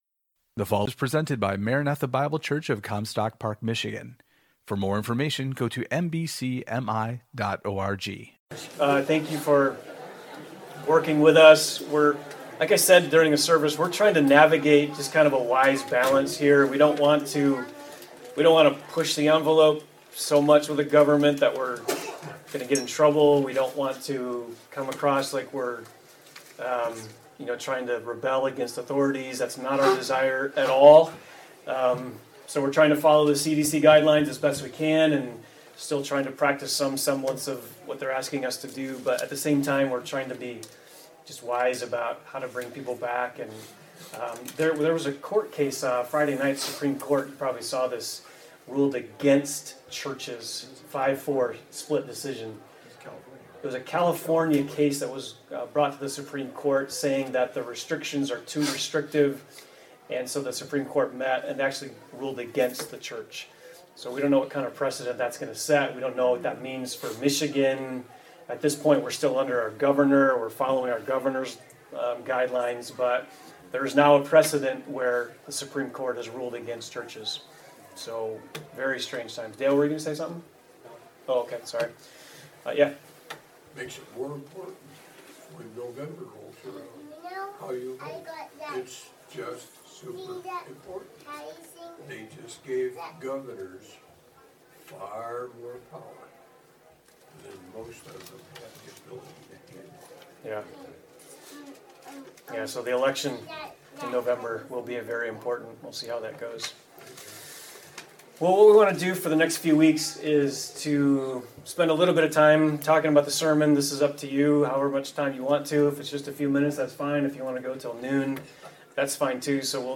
Equipping Hour: Sermon Discussion